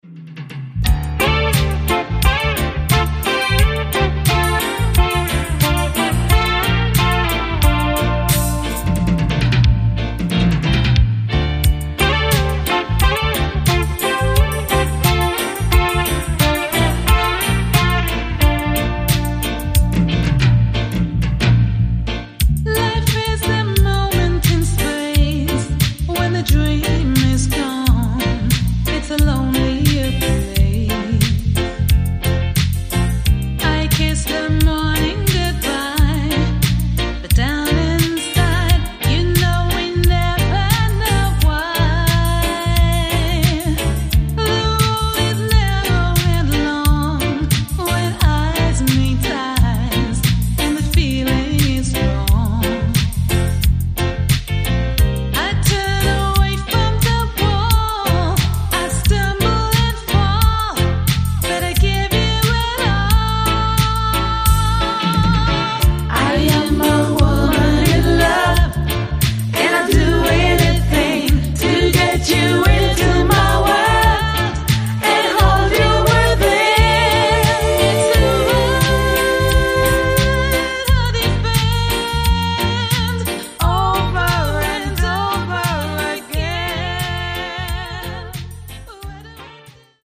female Reggae & Lovers